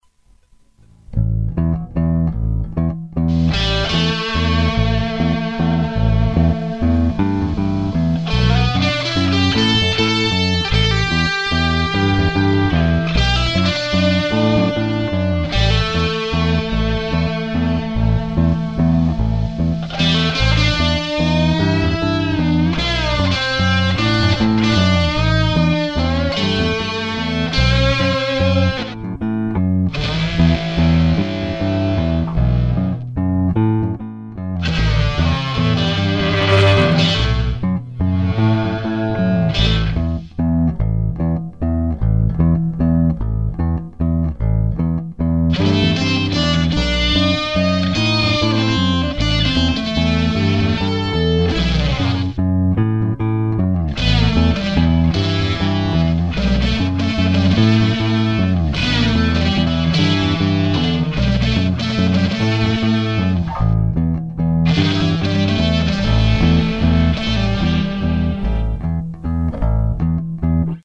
Bass&GuitarDAWtest2toomuchpan&noise